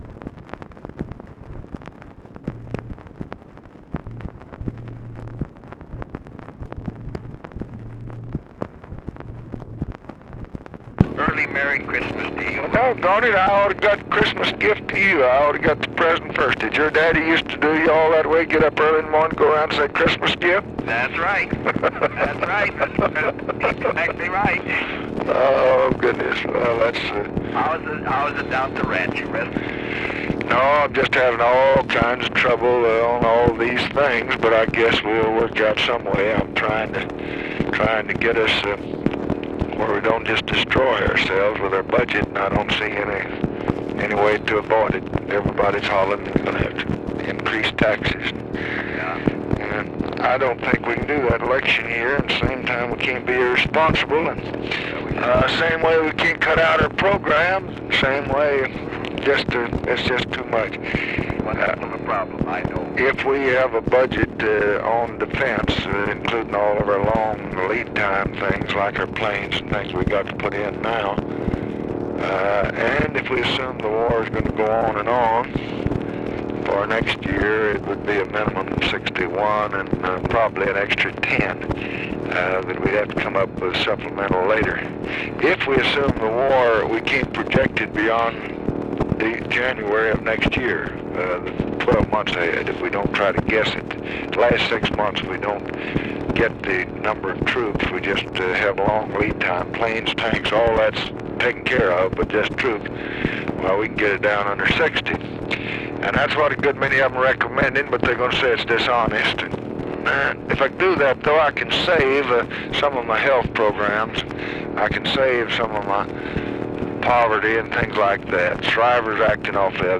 Conversation with HUBERT HUMPHREY, December 24, 1965
Secret White House Tapes